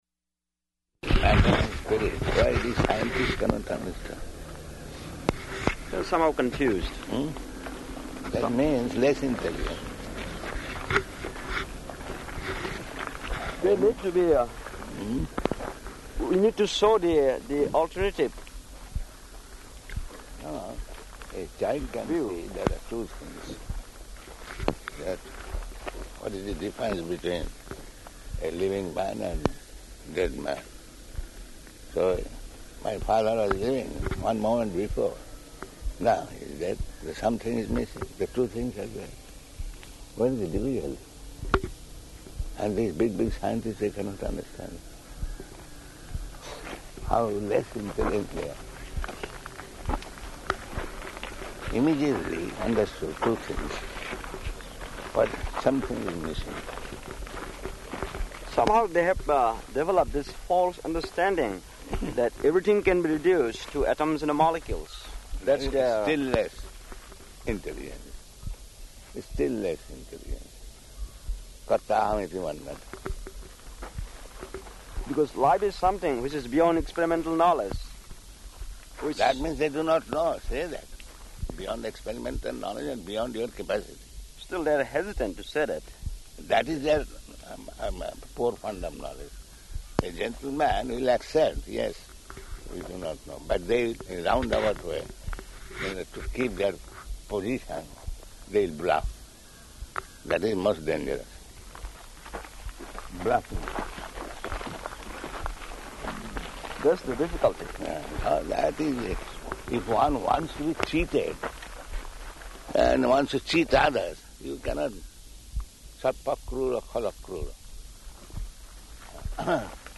Morning Walk --:-- --:-- Type: Walk Dated: February 1st 1977 Location: Bhubaneswar Audio file: 770201MW.BHU.mp3 Prabhupāda: ...matter and spirit.